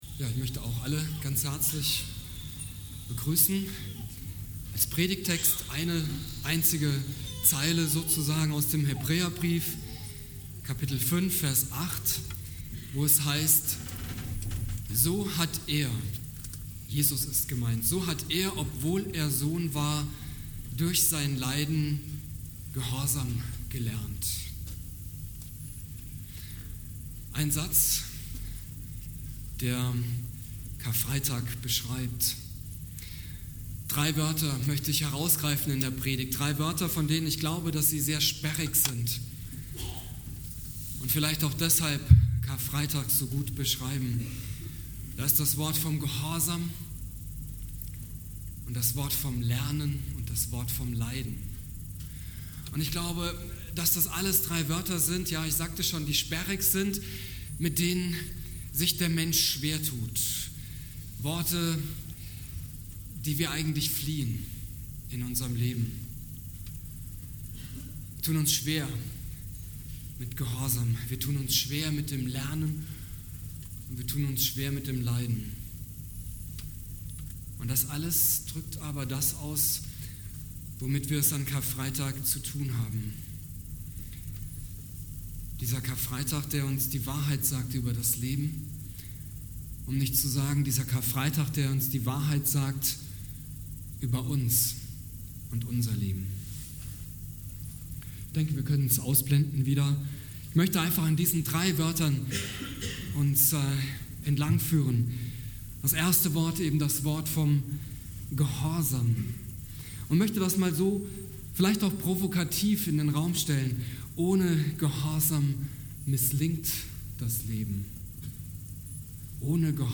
Predigt
Karfreitag